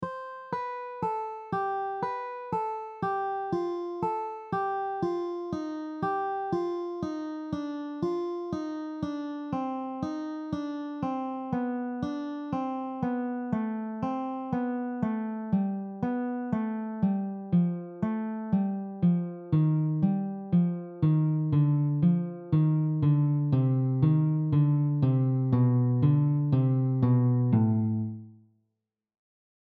The following patterns are all based on the C melodic minor scale.
4 note descending scale pattern
This is the same 4 note concept as the previous example except we are descending the melodic minor scale.